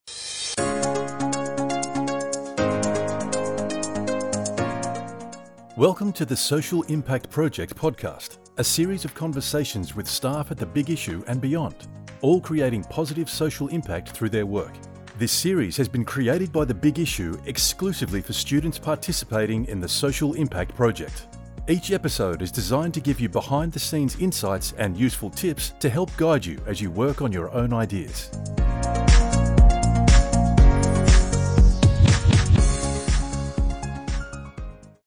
Rode NT-1, Sennheiser 416 microphones